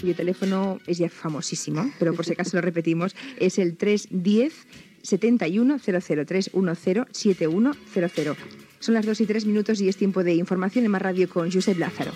Info-entreteniment
Otero, Julia